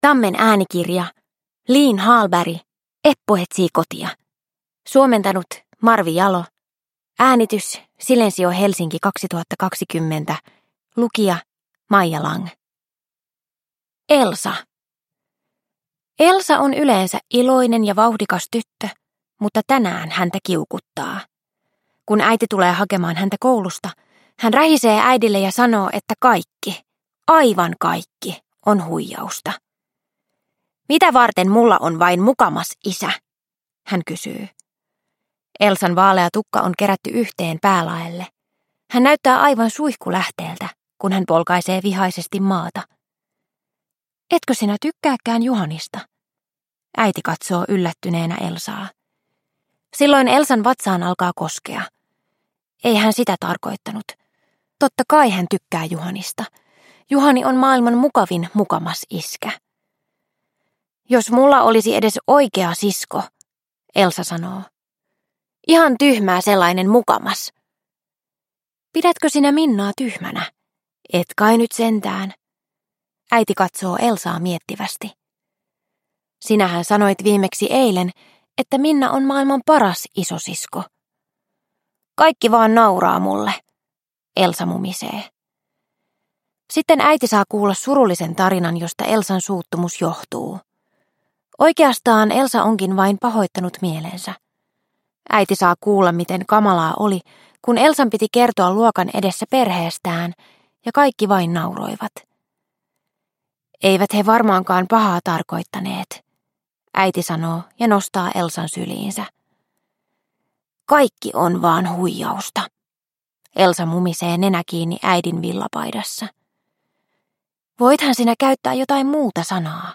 Eppu etsii kotia – Ljudbok – Laddas ner